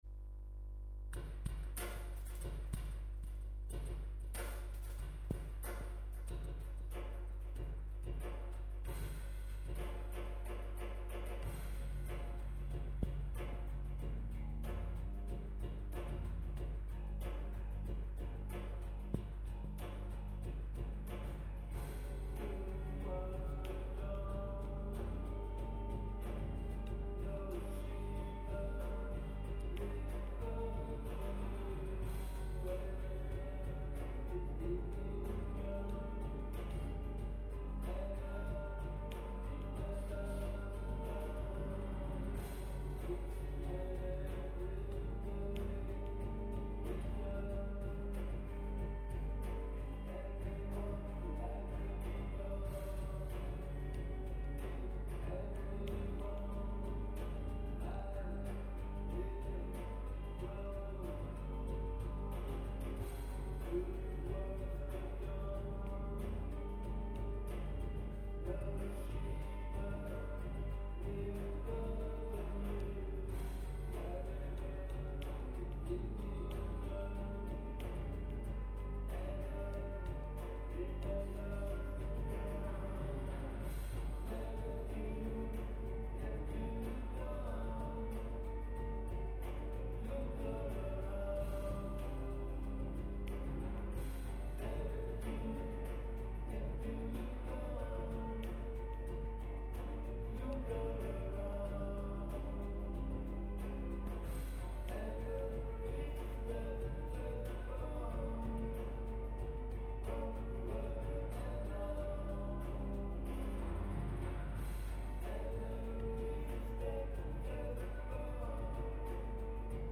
guitar
drums programming
bass and lead vocals